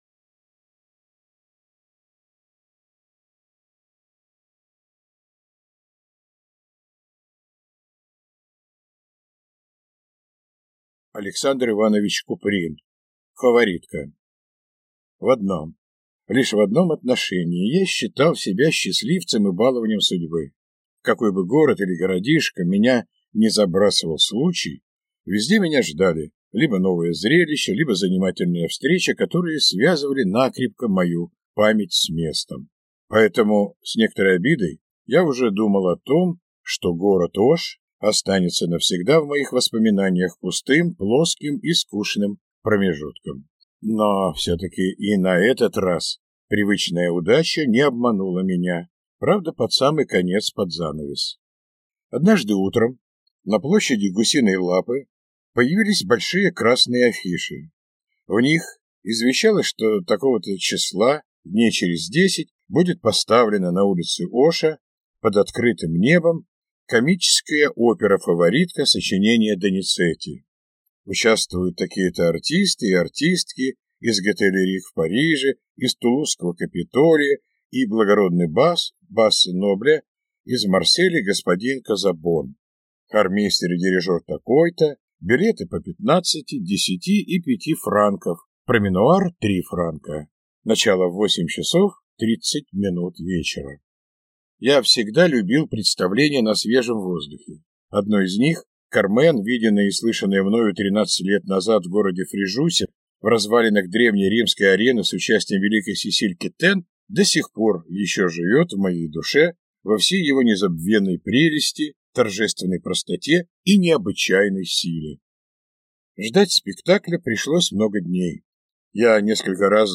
Аудиокнига «Фаворитка» | Библиотека аудиокниг
Aудиокнига «Фаворитка»